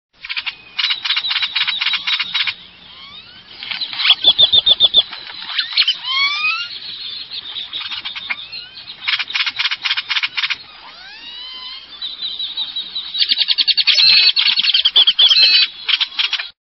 Great-tailed Grackle
Bird Sound
Song a loud series of harsh rattles interspersed with whistling notes and other noises.
Great-tailedGrackle.mp3